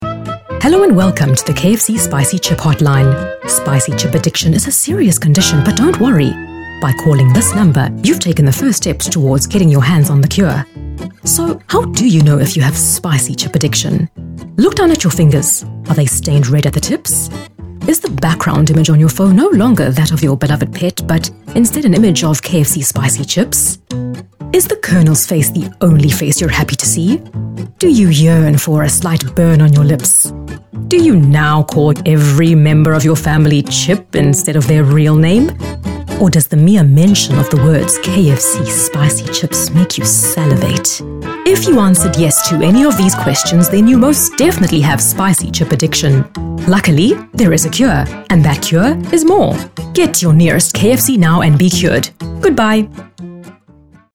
South Africa
Vocal Styles:
animated, lively, Smooth, spirited, vivacious
My demo reels